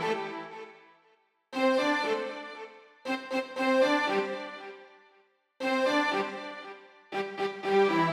28 Strings PT3.wav